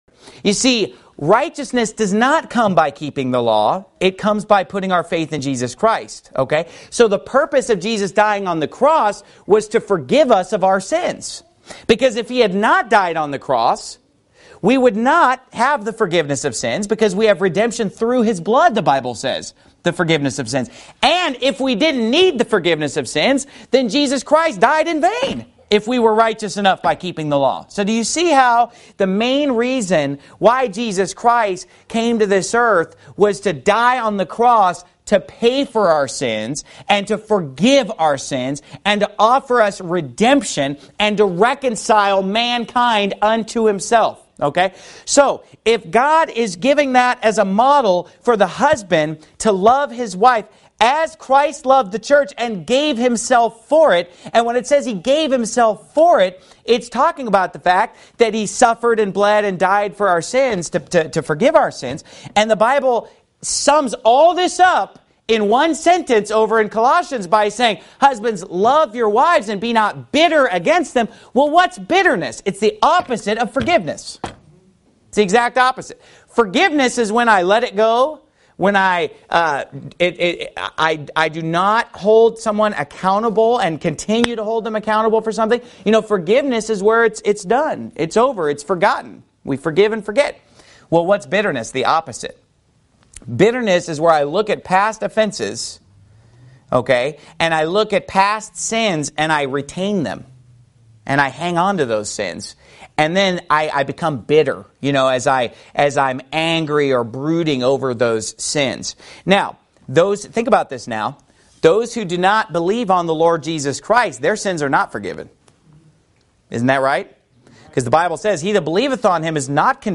Sermon Clips Pastor Steven Anderson